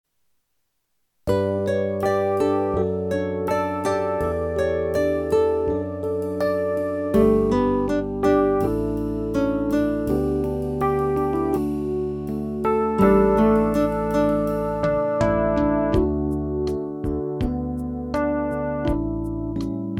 Playback zur Begleitung
MP3 Download (ohne Gesang)